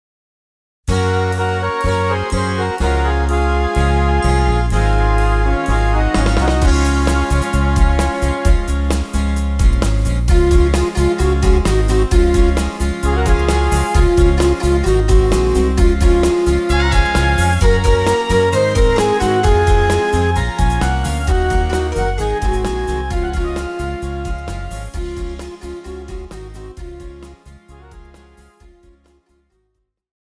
Žánr: Pohádkové
BPM: 125
Key: F#
MP3 ukázka